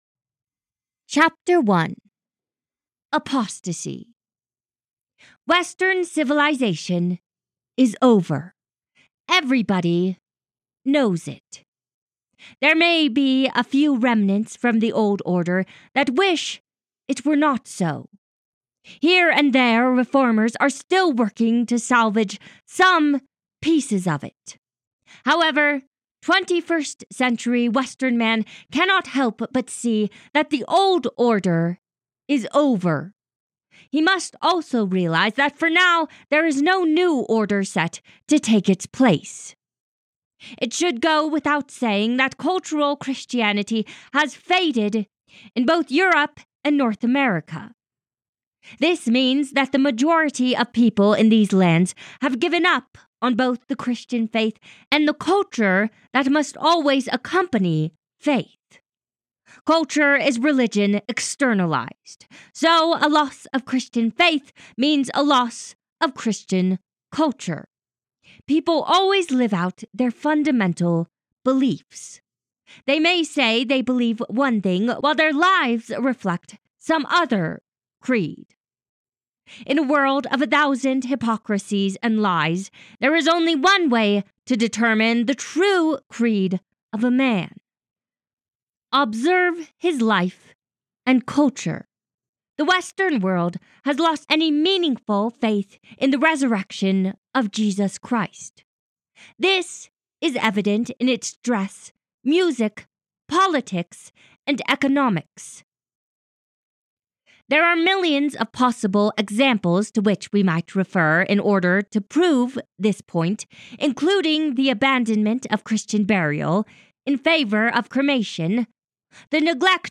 Apostate (2nd Edition) - Audiobook